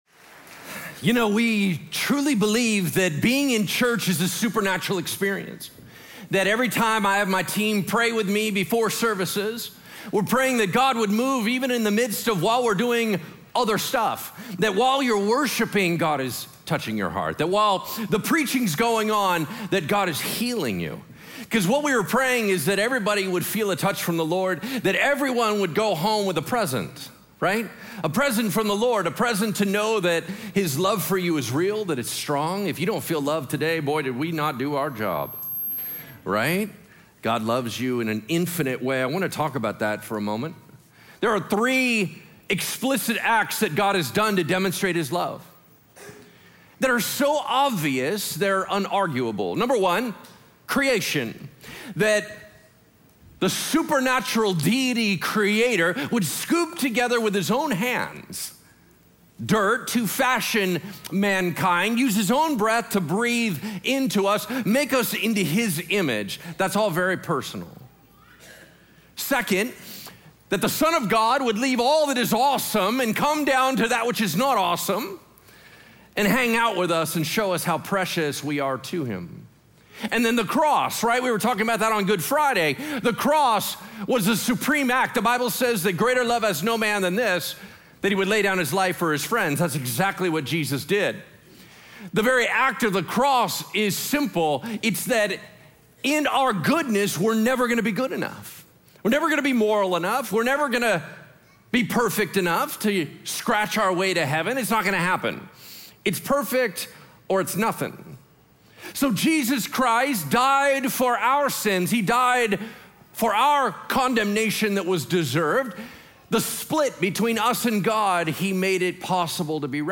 The Sacrificial Example of Jesus | John 20:19–29 | Easter at Bridgeway 2024